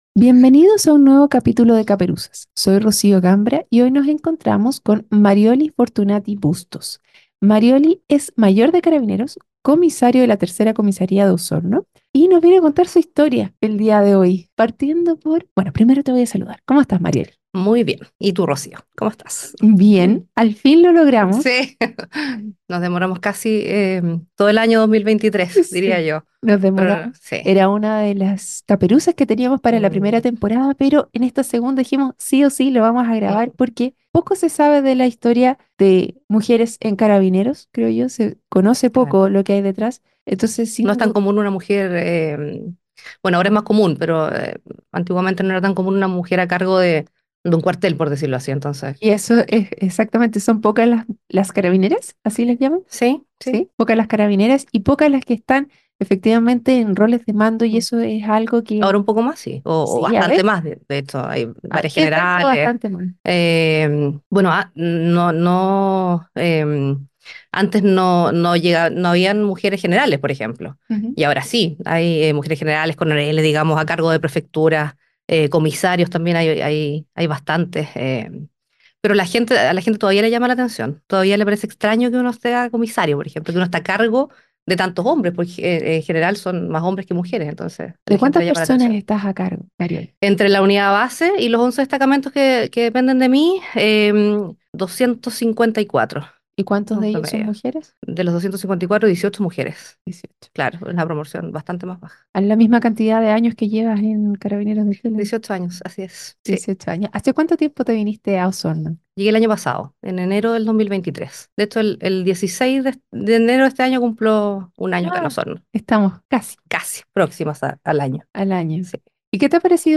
💬👮‍♀ Una charla imperdible donde se exploran historias, desafíos y éxitos...